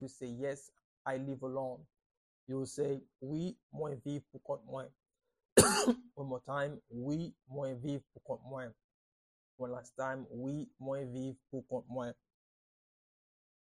Pronunciation and Transcript:
Yes-I-live-alone-in-Haitian-Creole-Wi-mwen-viv-pou-kont-mwen-pronunciation-by-a-Haitian-teacher.mp3